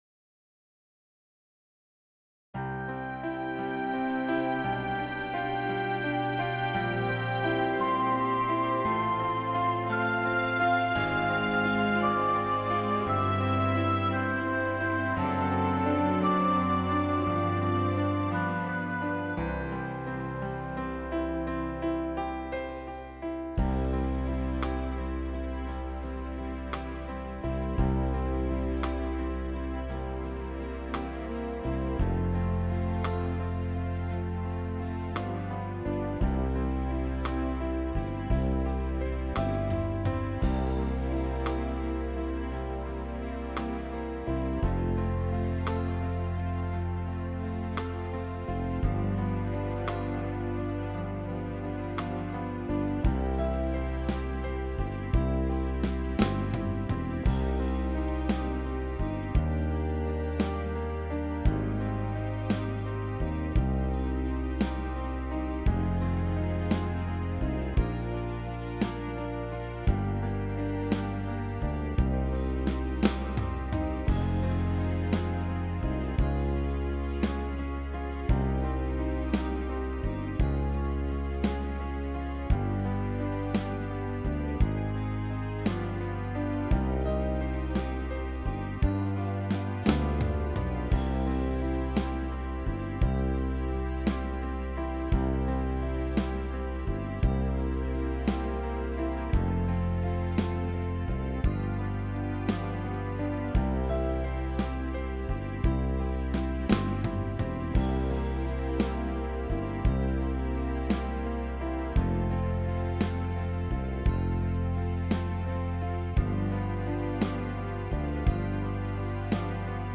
Tone Nữ (Cm)